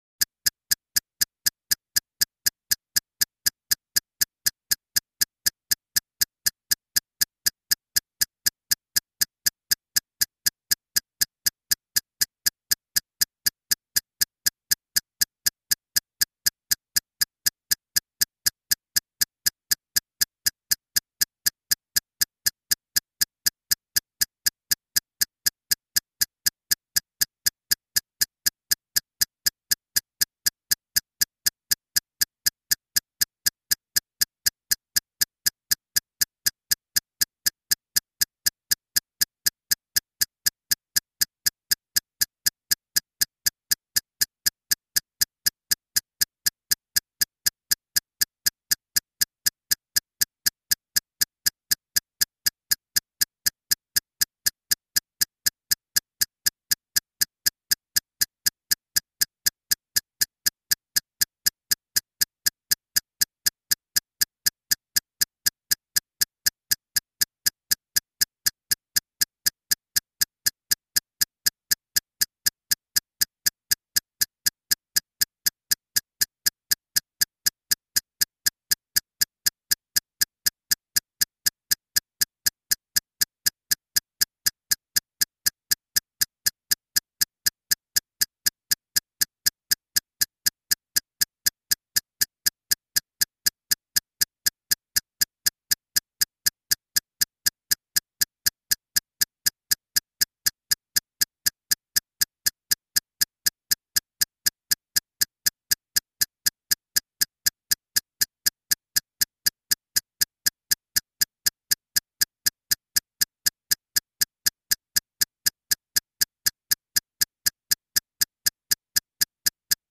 Antique Timer Clicks. And Servo Whine.